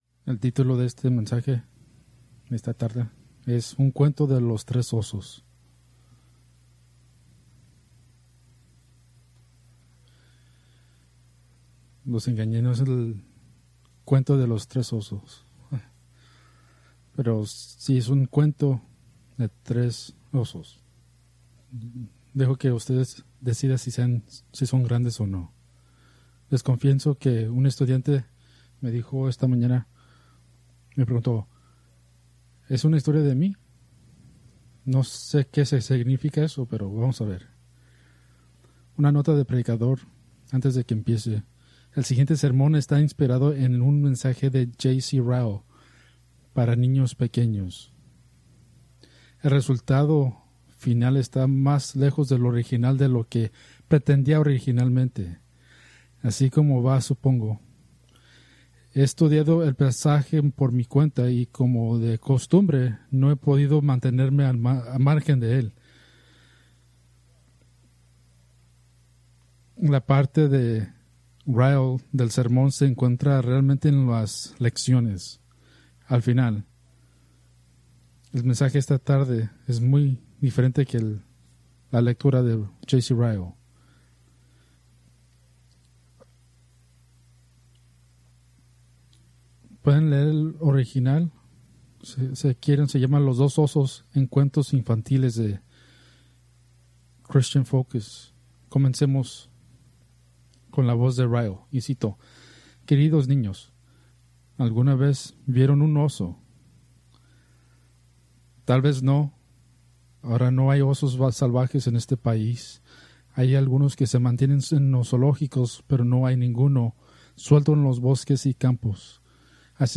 Preached July 13, 2025 from 2 Reyes 2:23-25